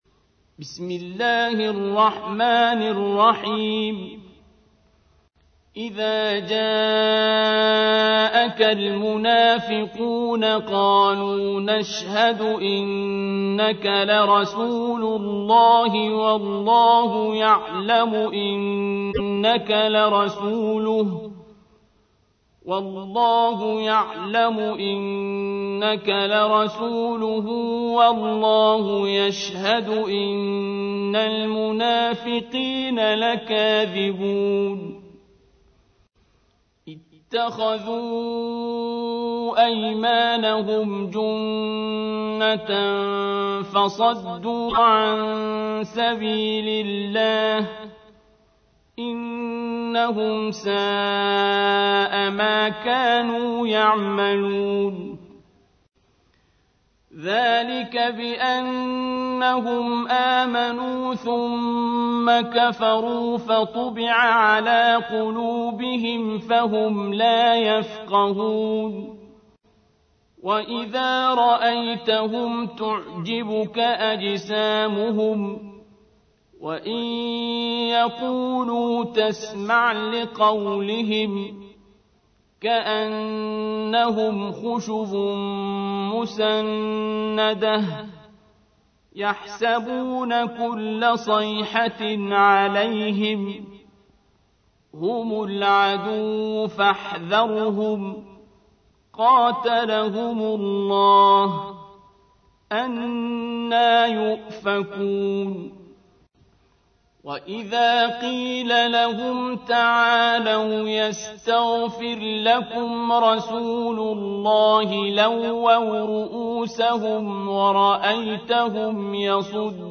تحميل : 63. سورة المنافقون / القارئ عبد الباسط عبد الصمد / القرآن الكريم / موقع يا حسين